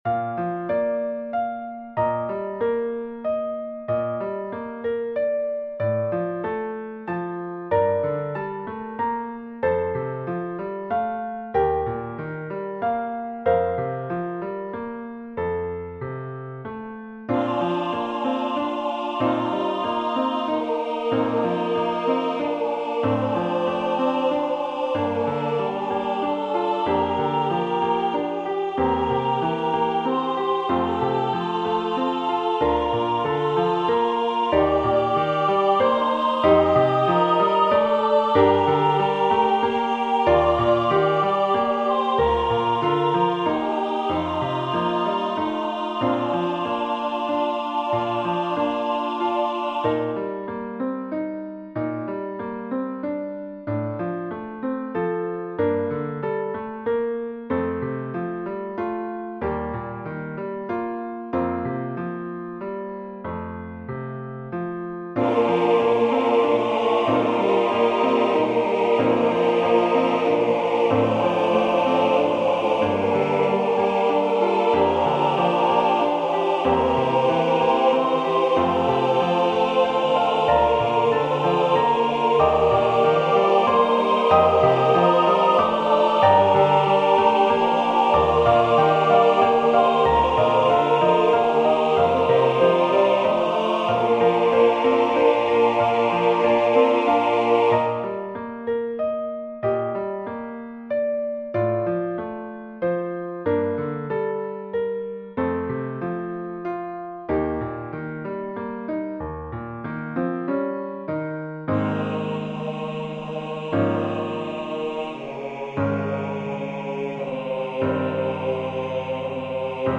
SATB with Piano Accompaniment
Voicing/Instrumentation: SATB